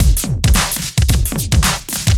OTG_DuoSwingMixC_110a.wav